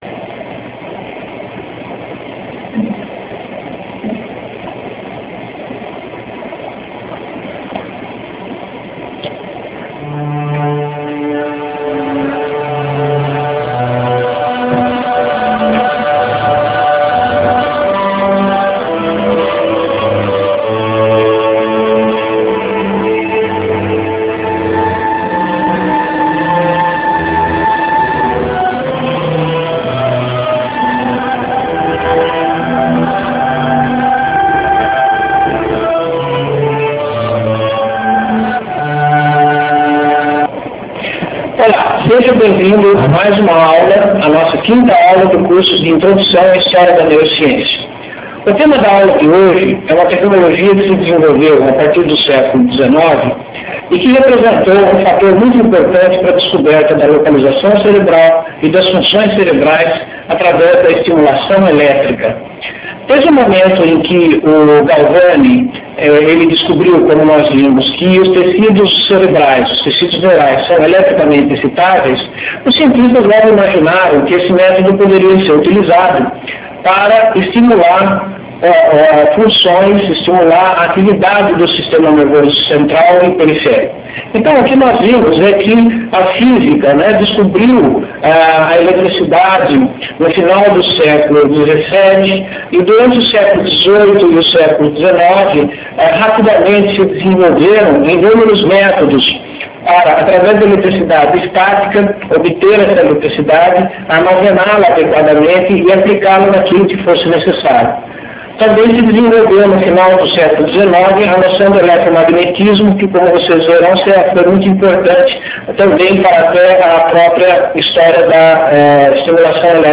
Assista a aula expositiva em rempo real, no formato de vídeo em RealMedia disponível no site do curso.